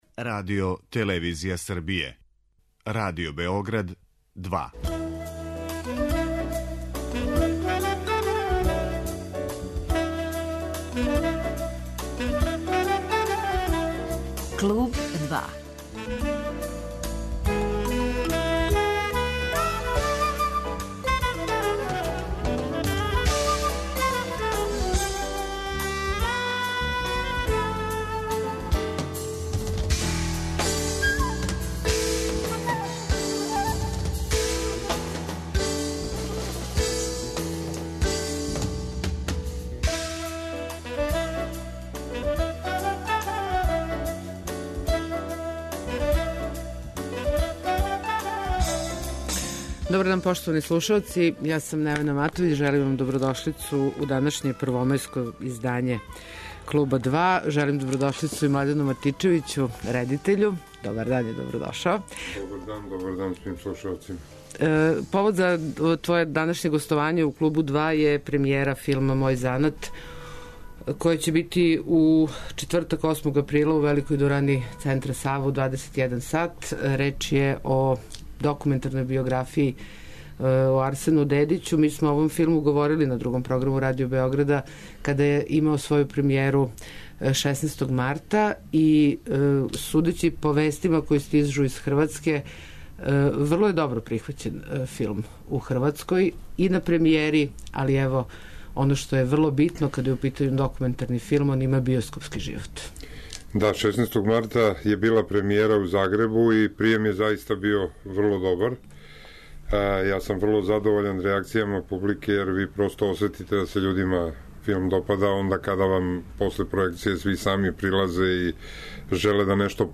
[ детаљније ] Све епизоде серијала Аудио подкаст Радио Београд 2 Сомерсет Мом: Паранг Мери Е. Брендон: Добра госпа Дукејн Андрија Мауровић: Тројица у мраку Жан Кокто: Антигона Срђан Вучинић: Драгутин Илић